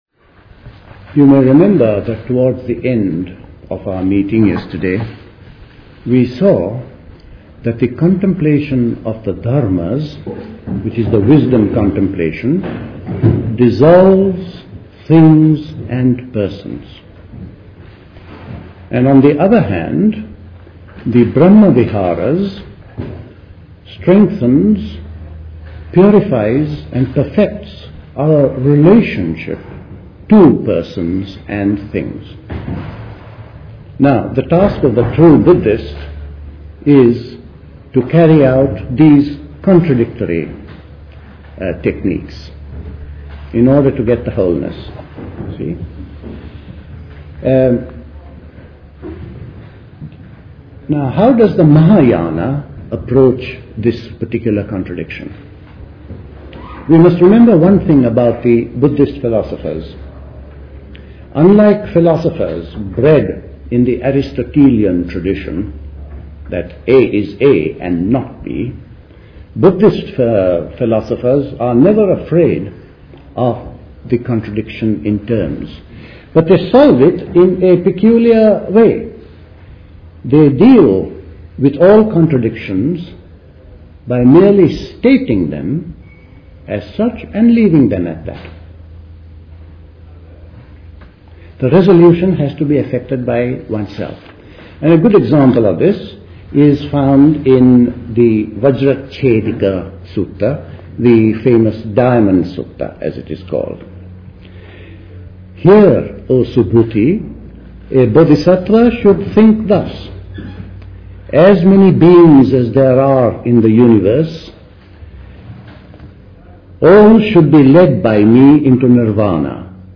Talk
The Buddhist Society Summer School Talks